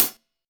Perc (219).wav